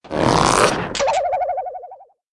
mailbox_full_wobble.ogg